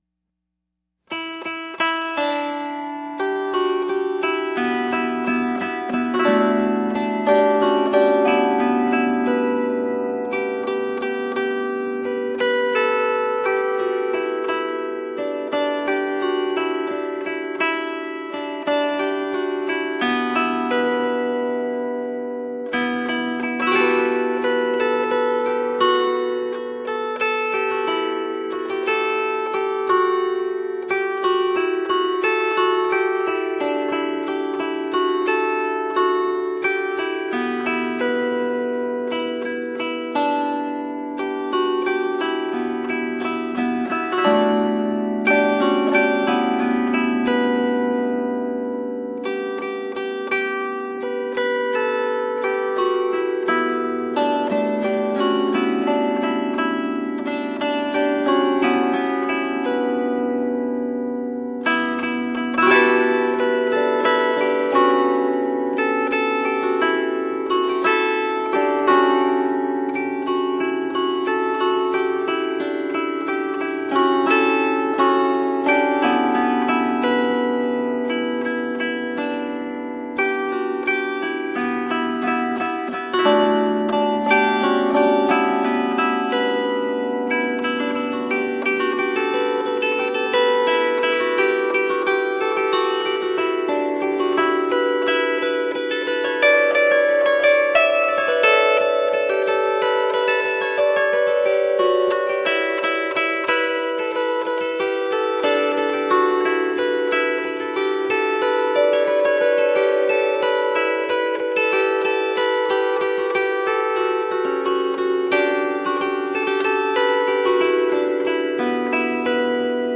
Medieval and middle eastern music.
traditional Breton